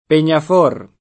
Peñafort [sp. pen’af 0 r ] top. (Sp.) — cat. Penyafort [ p H n’ 9 f 0 rt ] — it. Pennaforte [ pennaf 0 rte ]